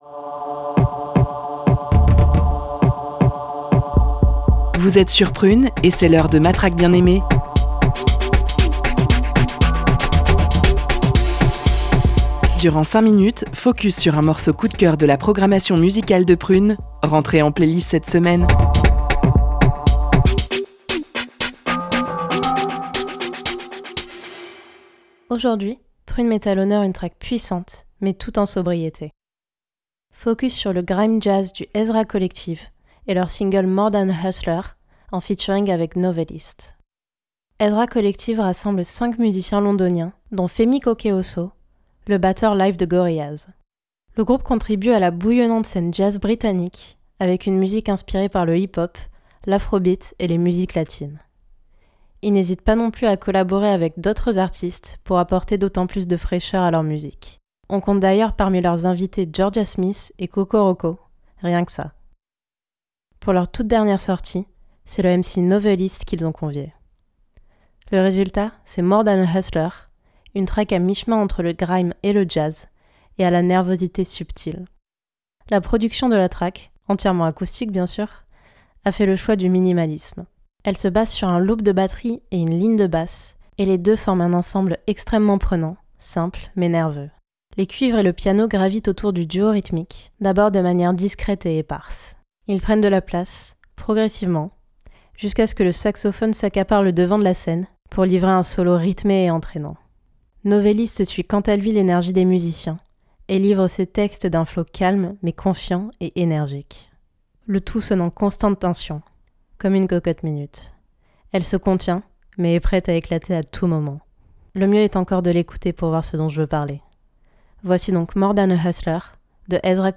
grime-jazz